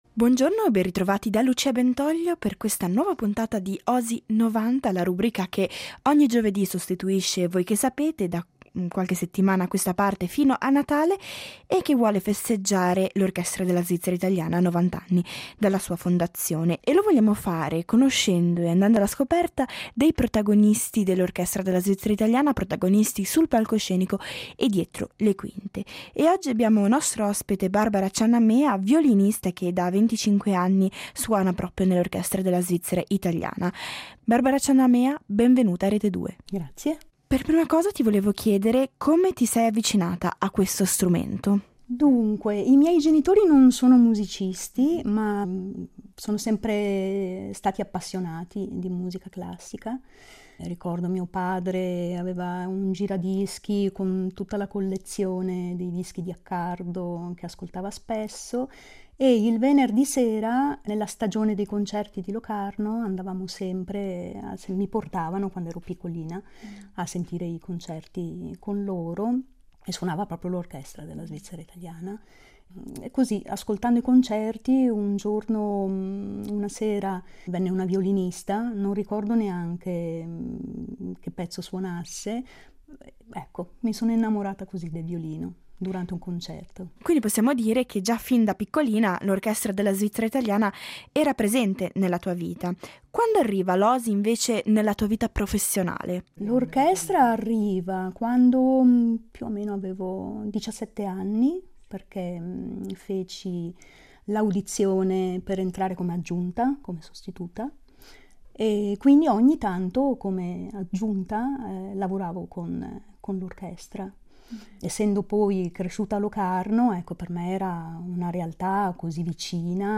Una serie di interviste per raccontare l’Orchestra della Svizzera italiana